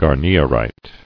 [gar·ni·er·ite]